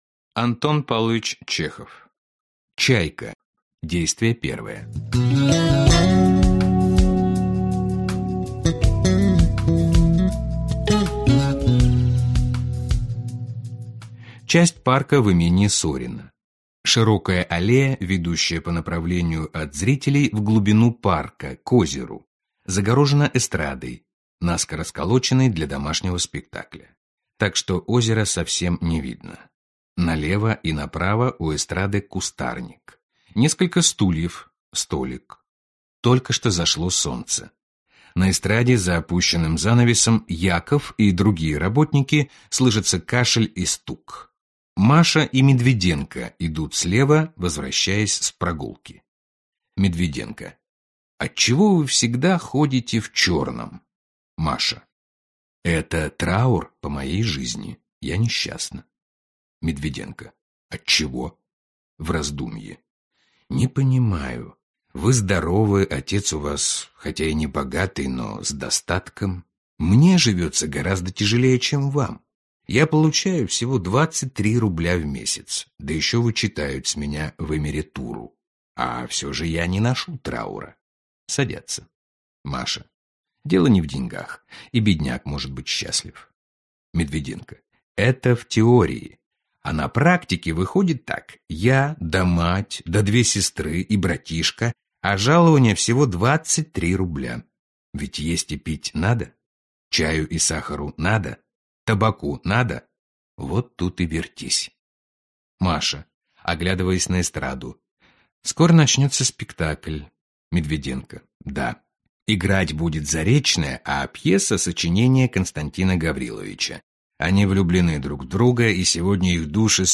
Аудиокнига Чайка | Библиотека аудиокниг